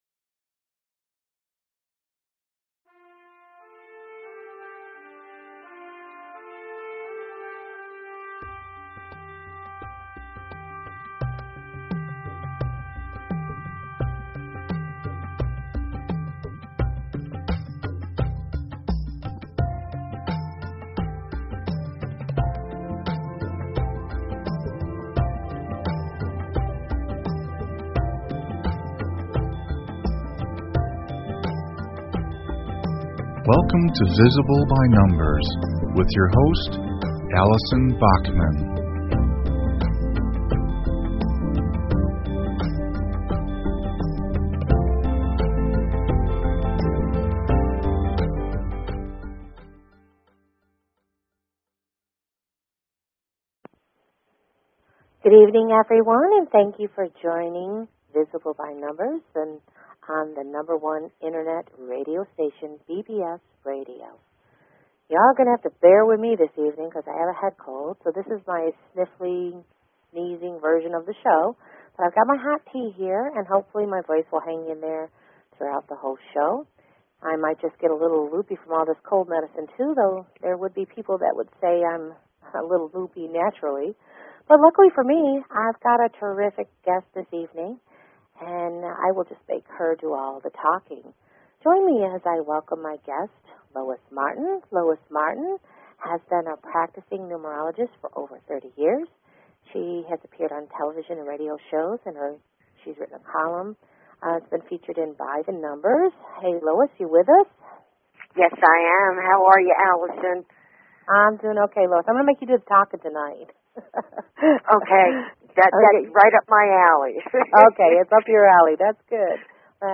Talk Show Episode, Audio Podcast, Visible_By_Numbers and Courtesy of BBS Radio on , show guests , about , categorized as
Well, the answer is having two Numerologists LIVE on BBS RADIO !~ Visible by Numbers Please consider subscribing to this talk show.